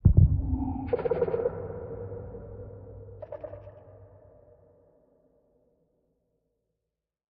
Minecraft Version Minecraft Version snapshot Latest Release | Latest Snapshot snapshot / assets / minecraft / sounds / mob / warden / nearby_closer_1.ogg Compare With Compare With Latest Release | Latest Snapshot